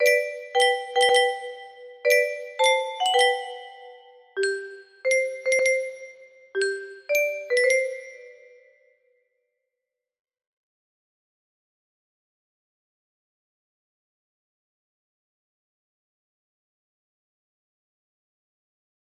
music box melody
melodía simple con medio tono